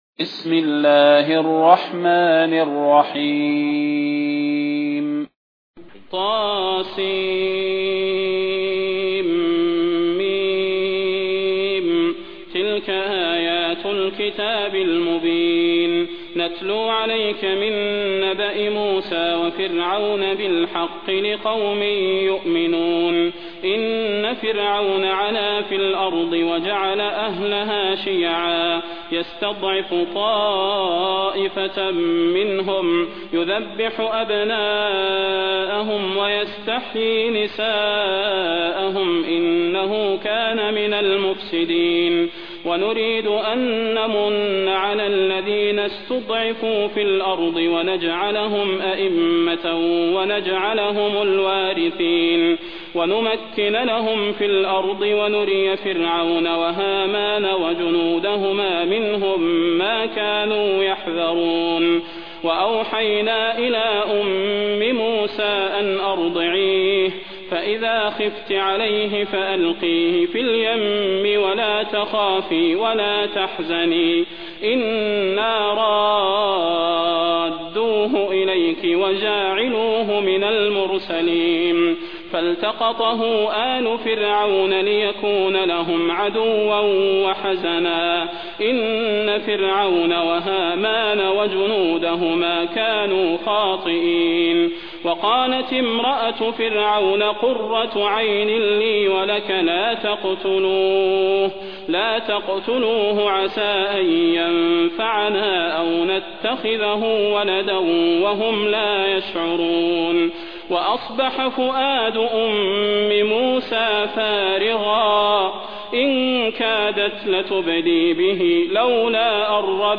تلاوة سورة القصص
المكان: المسجد النبوي الشيخ: فضيلة الشيخ د. صلاح بن محمد البدير فضيلة الشيخ د. صلاح بن محمد البدير سورة القصص